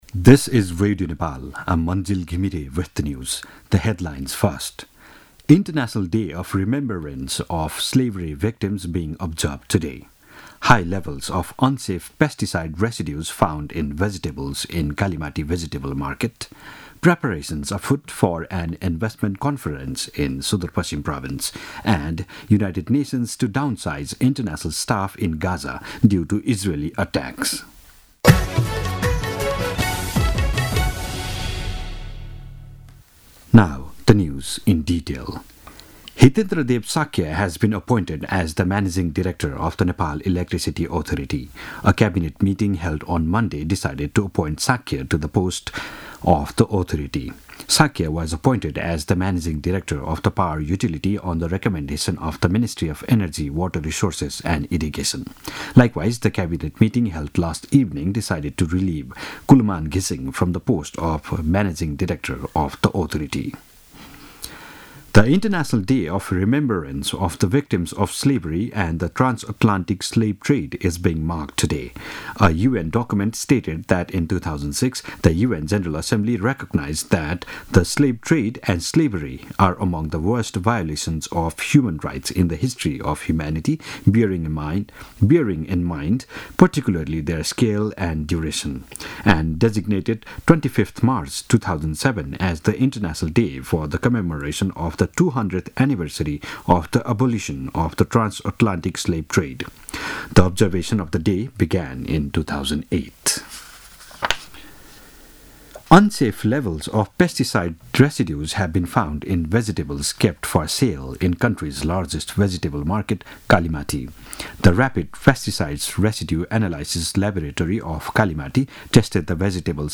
दिउँसो २ बजेको अङ्ग्रेजी समाचार : १२ चैत , २०८१
2-pm-news-1-9.mp3